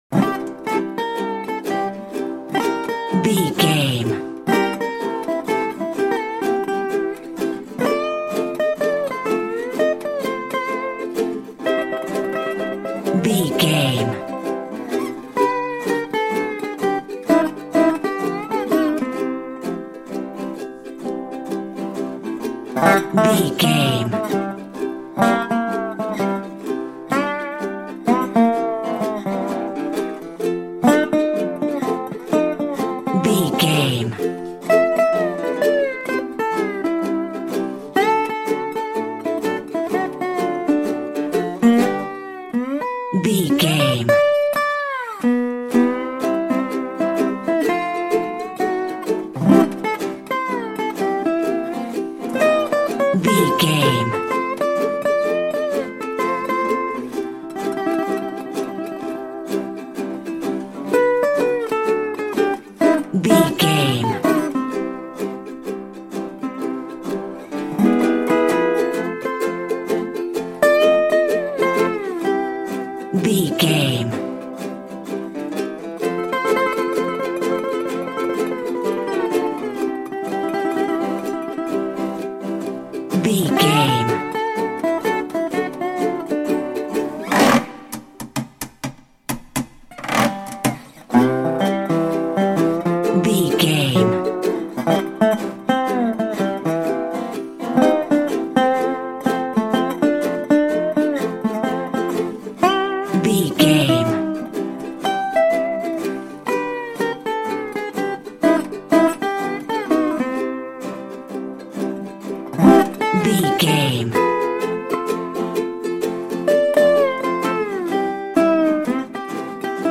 Ionian/Major
acoustic guitar
percussion
ukulele
dobro
slack key guitar